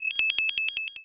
Add sound effect assets.
goal.wav